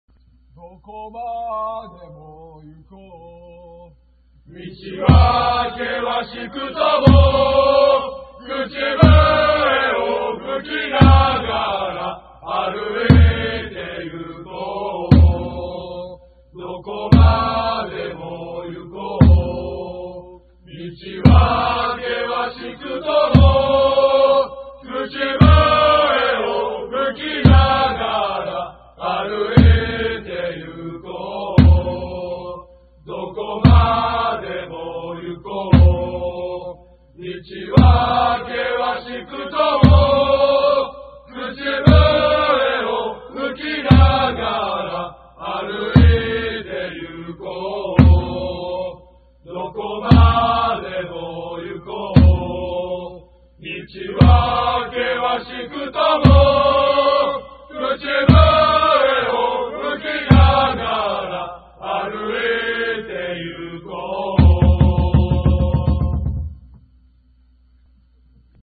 チームのチャント